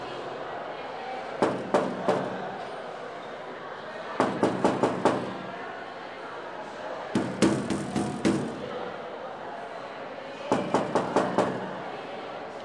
吱吱作响的关闸声 " 关闸，走人
描述：有人打开吱吱作响的金属牛门，在小河上的桥上走去，而门则落下。 背景中可以听到小溪的声音。用松下Lumix相机拍摄的未经编辑的现场录音。
Tag: 吱吱栅极 金属栅极 浇口闭合 牛栅 现场记录 行走远